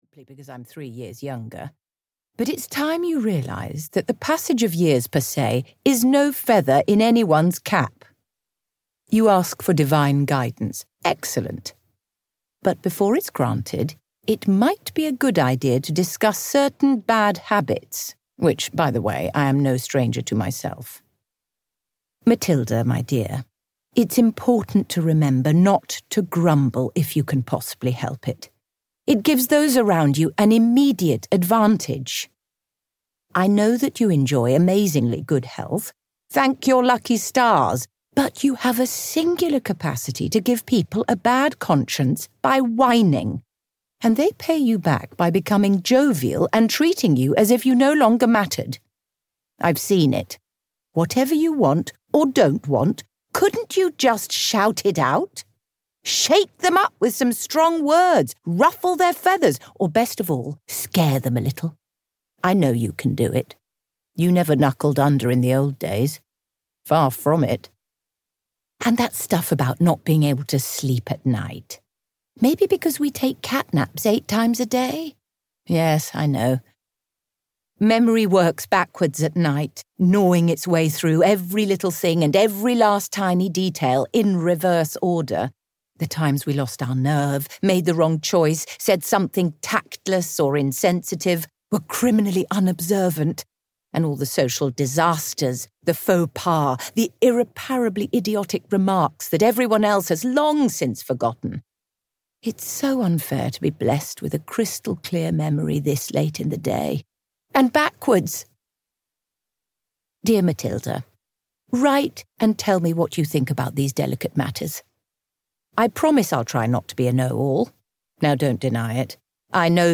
Letters from Klara (EN) audiokniha
Ukázka z knihy
• InterpretIndira Varma